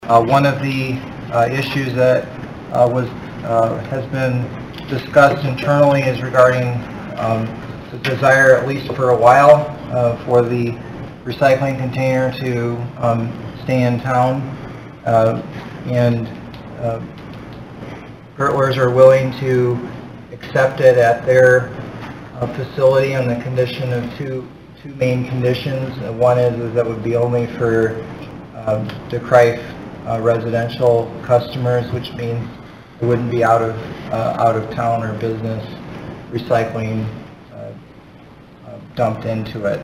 The Council had voted last year to remove the downtown recycling collection container from the city parking lot on the north side of the Library in January, 2024. Kooiker addressed that issue at Wednesday’s council meeting.